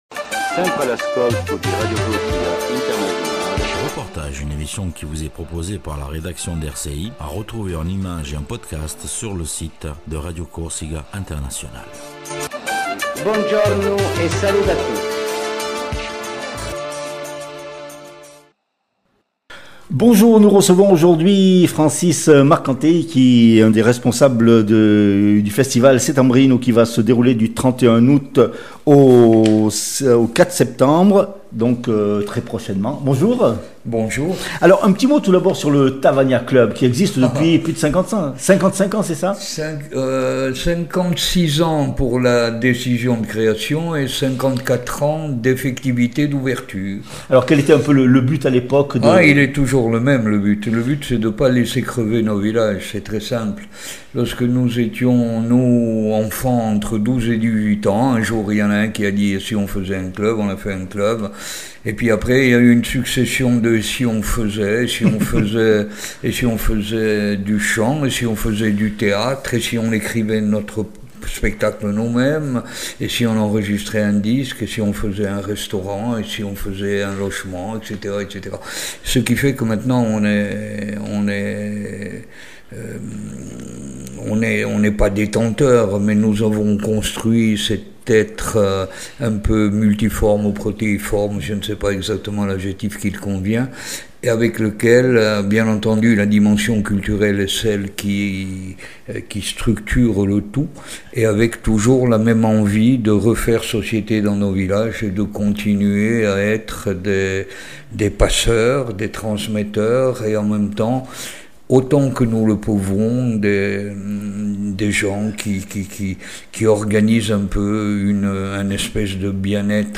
REPORTAGE FESTIVAL U SETTEMBRINU DI TAVAGNA .